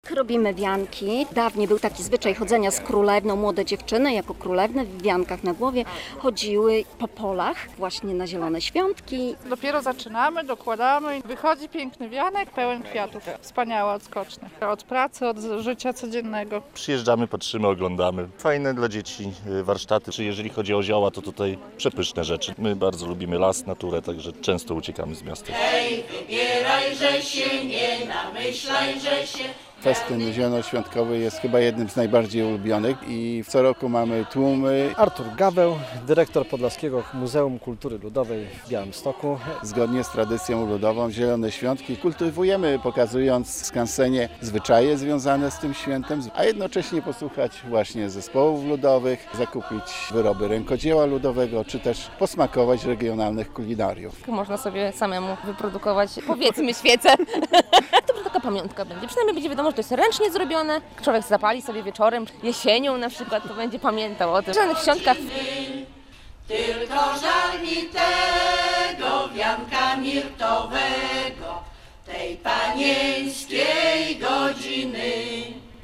Zielone Świątki w Podlaskim Muzeum Kultury Ludowej - relacja